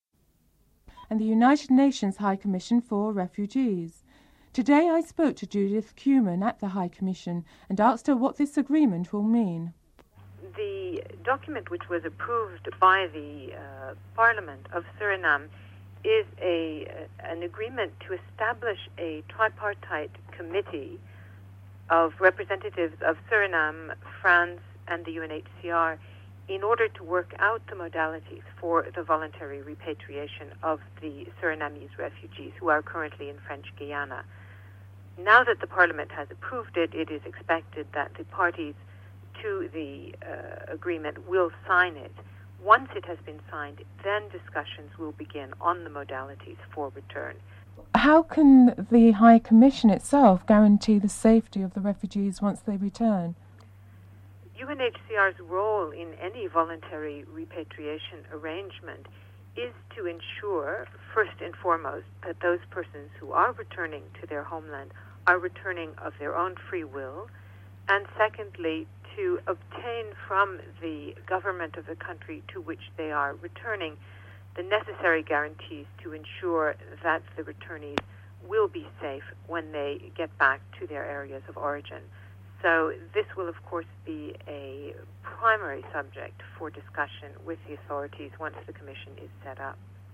Headlines segment missing from this program.